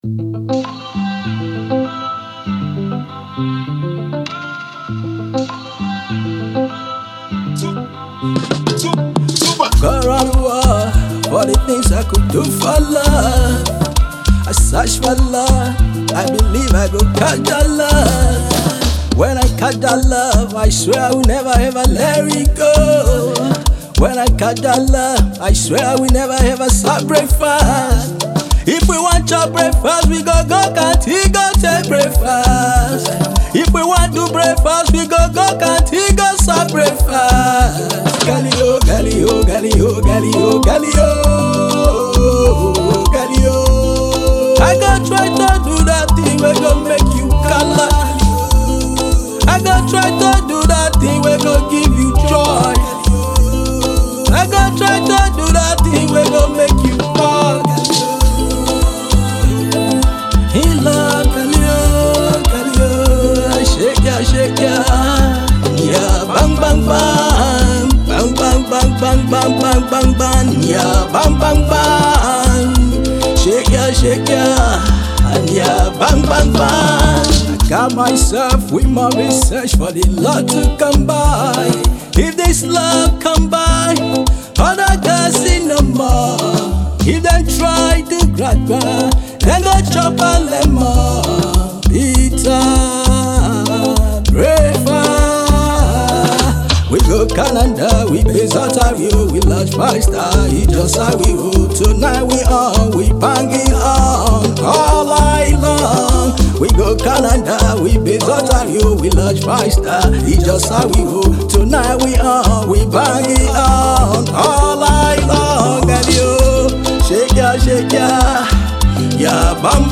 The genres are Afrobeat and Afro-fusion.